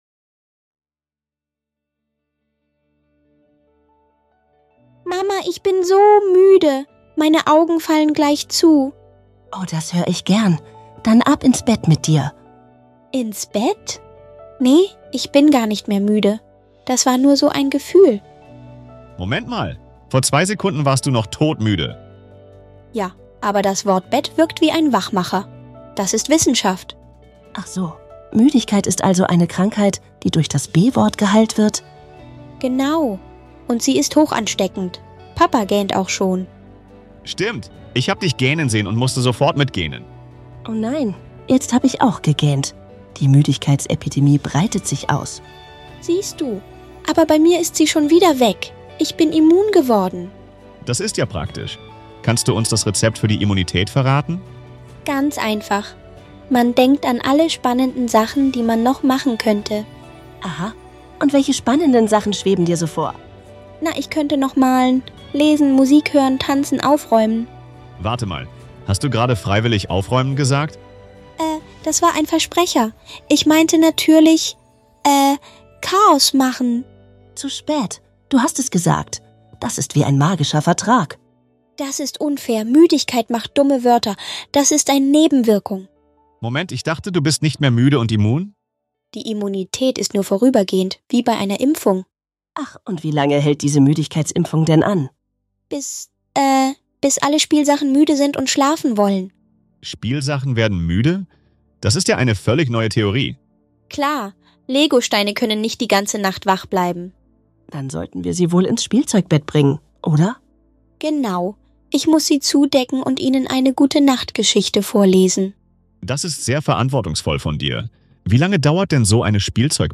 In diesem lustigen Gespräch zwischen einer Familie entdecken